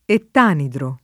[ ett # nidro ]